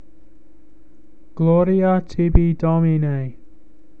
Glor-ee-ah tee-bee,  dom-  ee-nay.